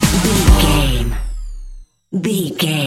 Uplifting
Aeolian/Minor
Fast
drum machine
synthesiser
electric piano